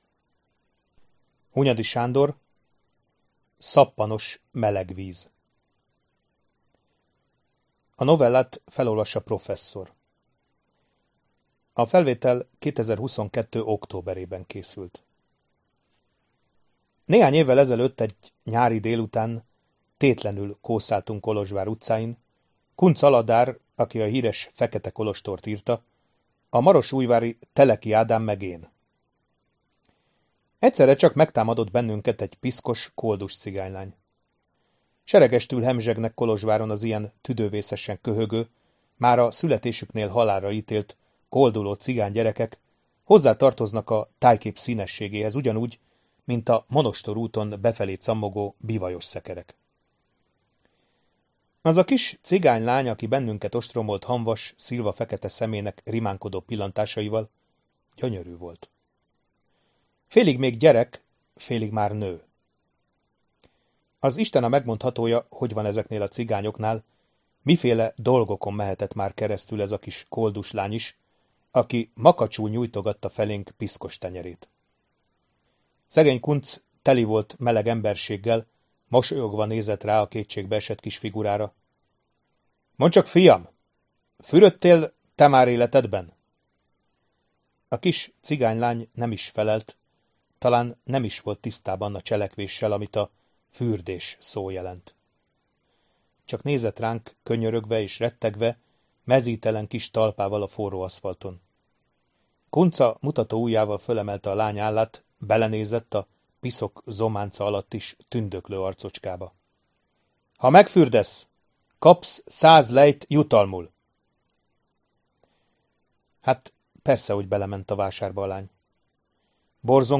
HANGOSKÖNYV fájlok letöltése: Razzia az ”Arany Sas”- ban (novella) Hunyady Sándor – Szappanos, meleg víz (novella)